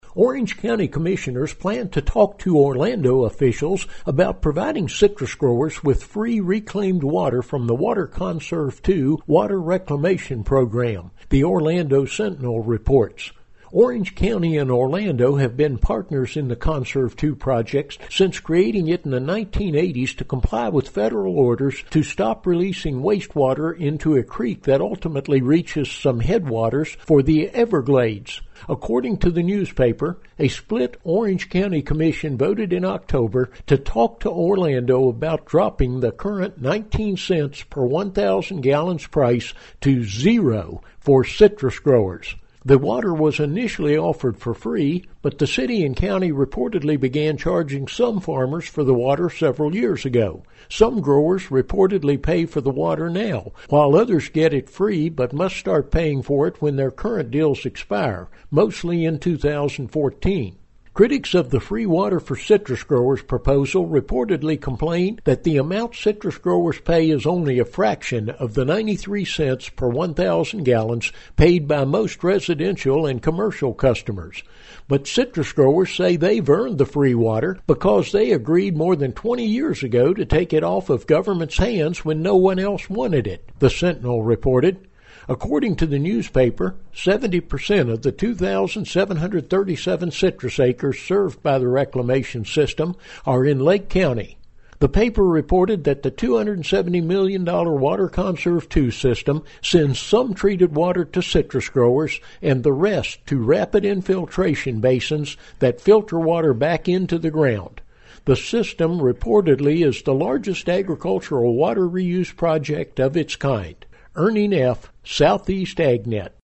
This report has more information.